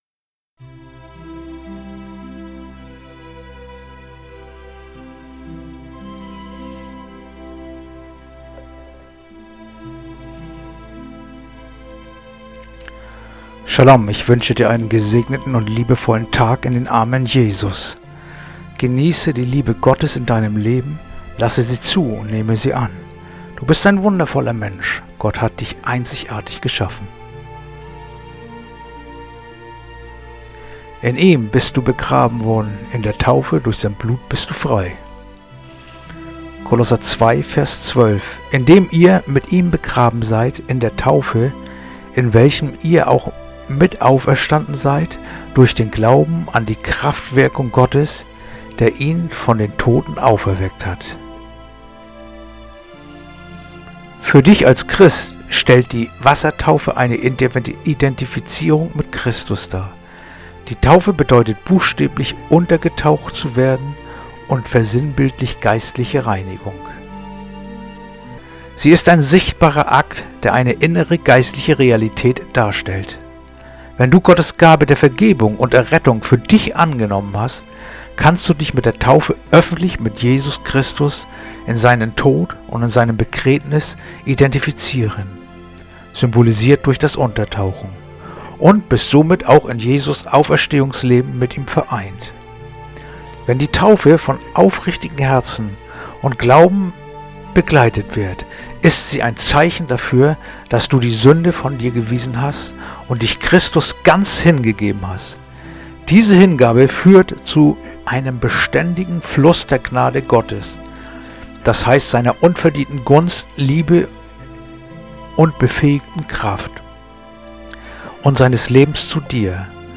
heutige akustische Andacht
Andacht-vom-16.-November-Kolosser-2-12.mp3